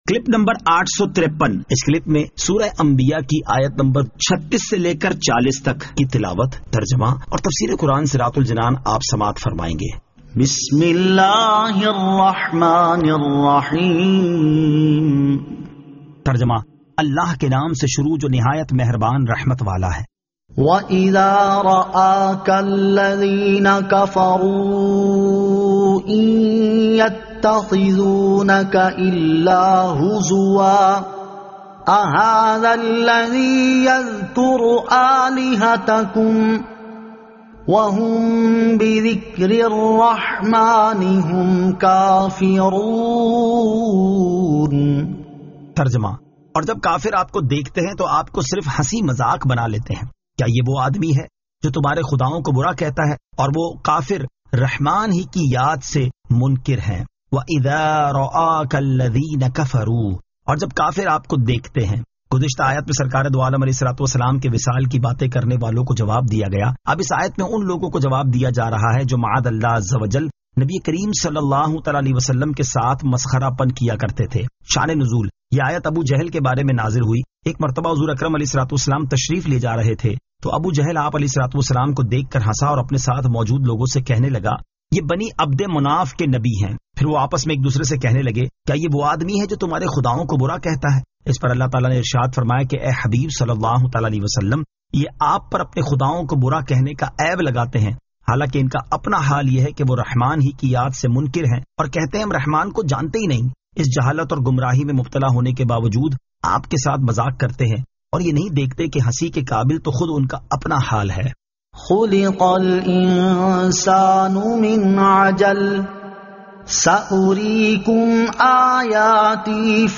Surah Al-Anbiya 36 To 40 Tilawat , Tarjama , Tafseer